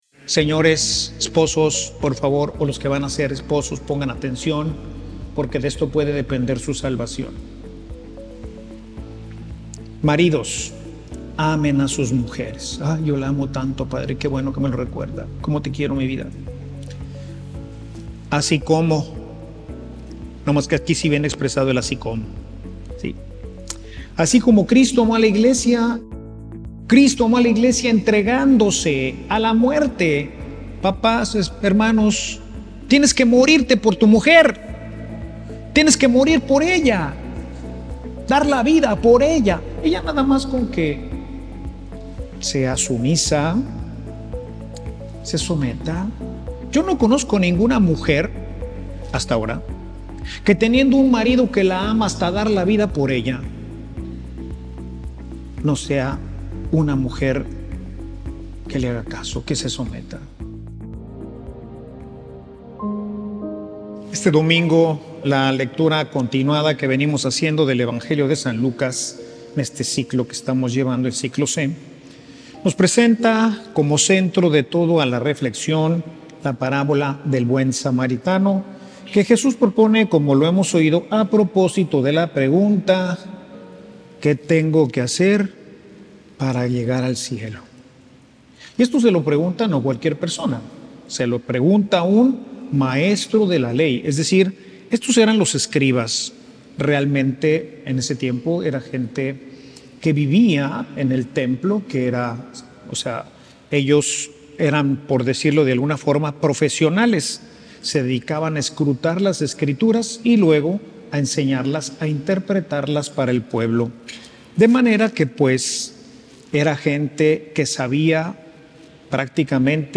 homilia
Homilia_Que_debo_hacer_para_llegar_al_cielo.mp3